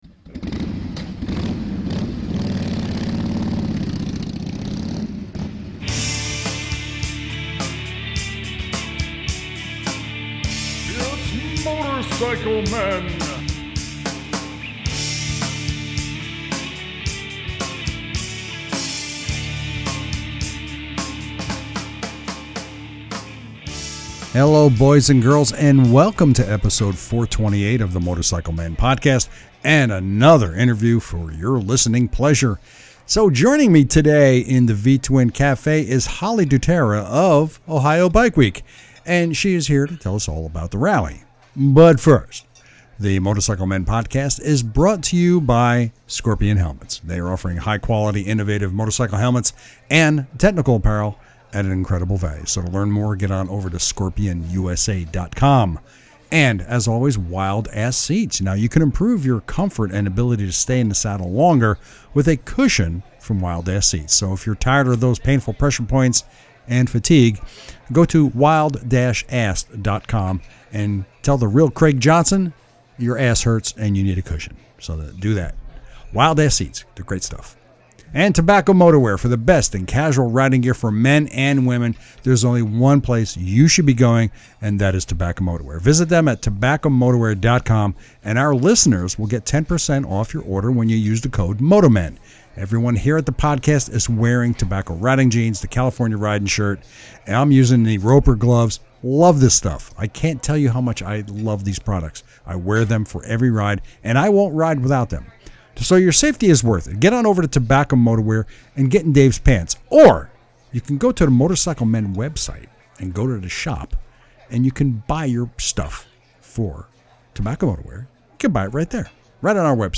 Episode 428 - Interview